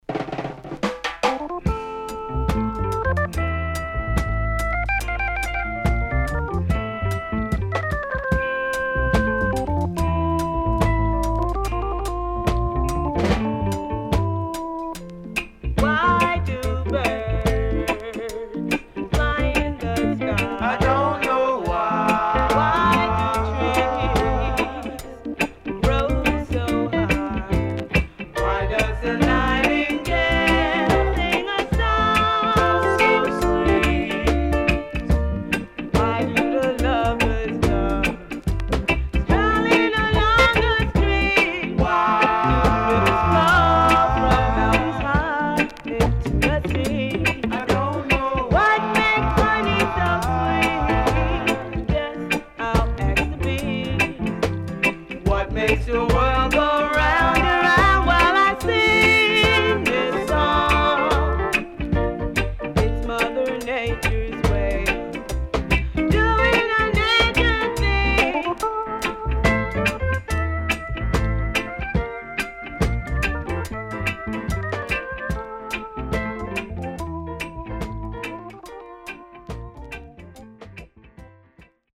SIDE B:少しチリノイズ、プチノイズ入りますが良好です。